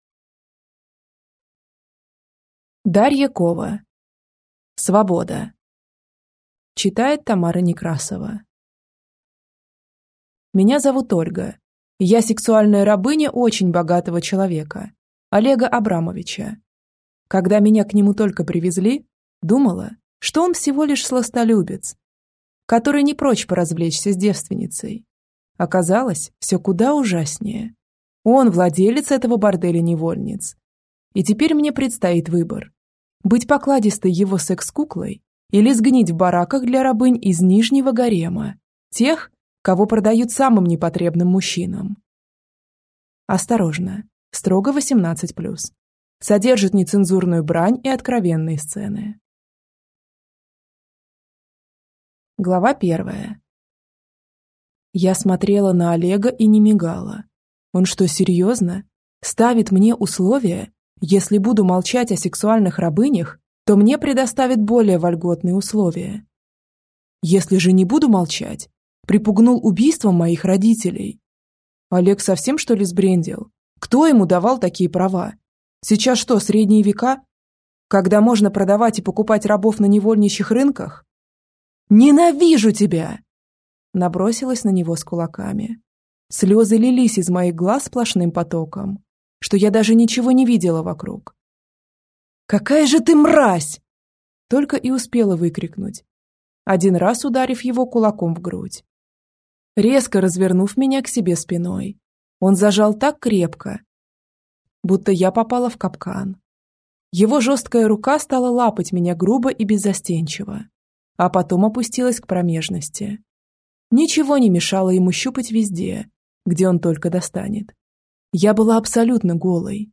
Аудиокнига Свобода | Библиотека аудиокниг